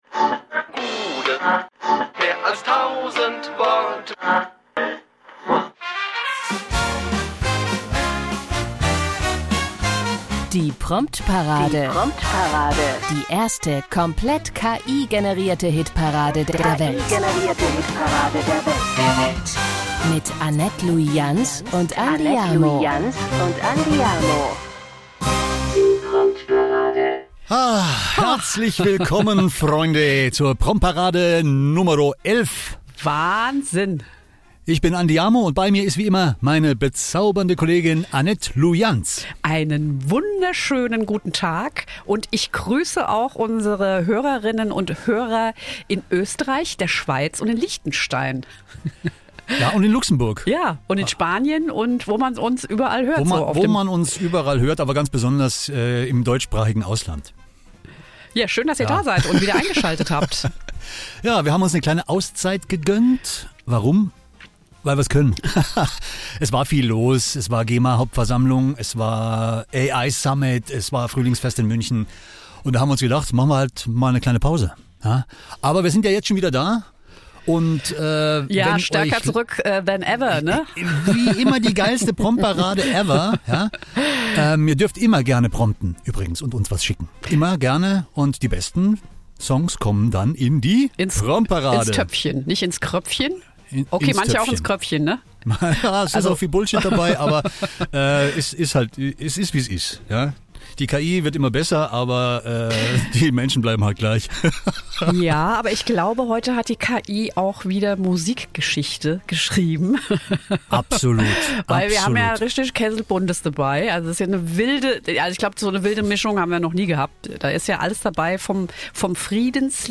Alle Songs wurden zumindest komplett von einer künstlichen Intelligenz umgesetzt.